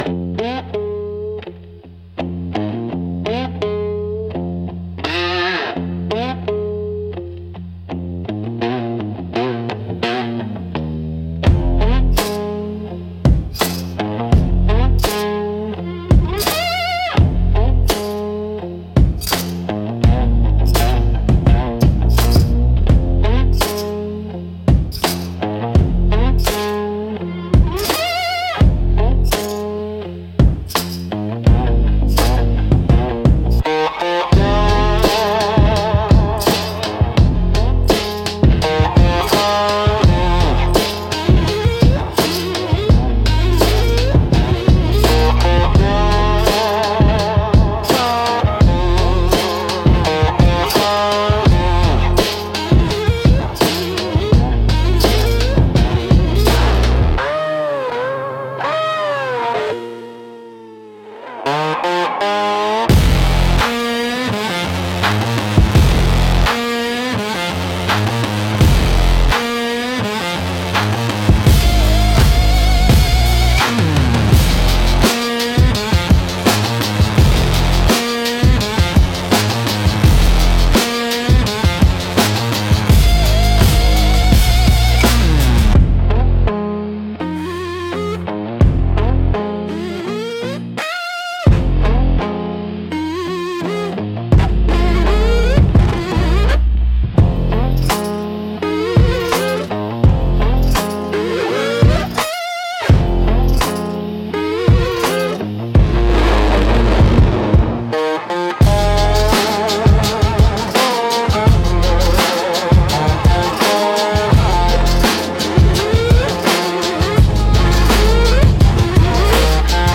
Instrumental - Ashes in the Feedback 3.17